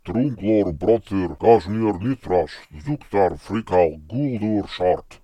It incorporates a range of vocalizations, alternating between normal voice production and deep, guttural growls, reminiscent of the Fomorians' intimidating presence.